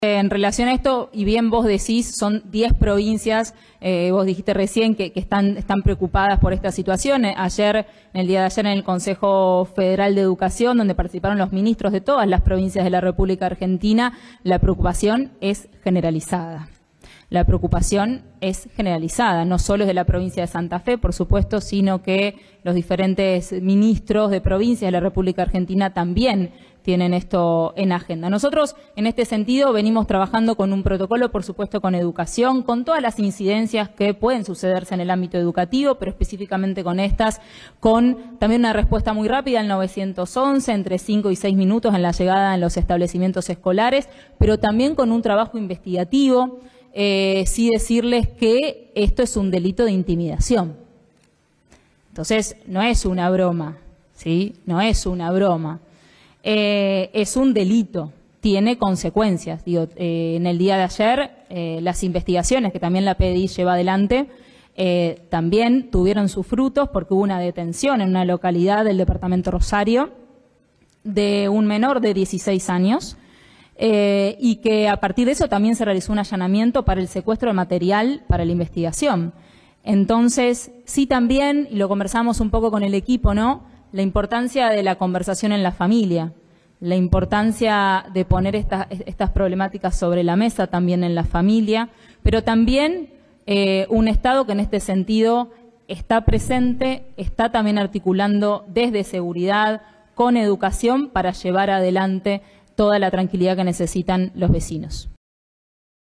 Declaraciones de Coudannes